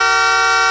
blowSoundPlayIt.wav